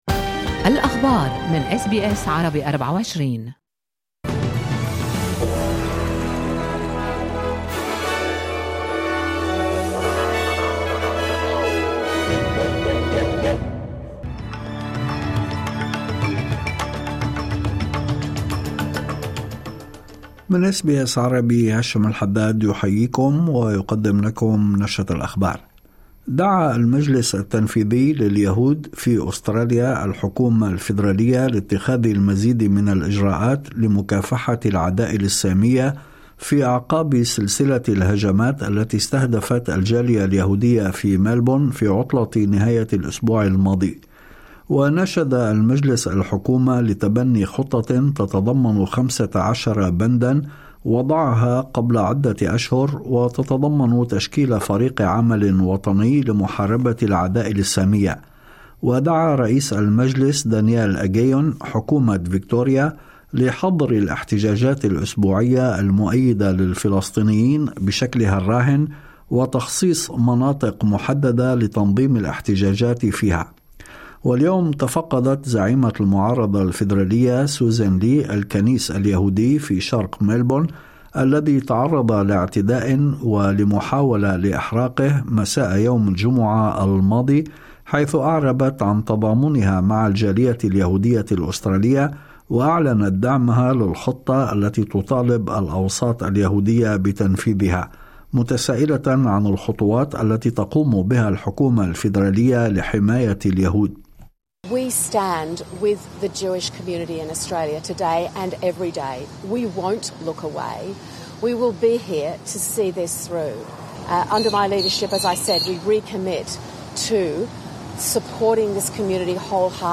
نشرة أخبار الظهيرة 8/7/2025
نشرة الأخبار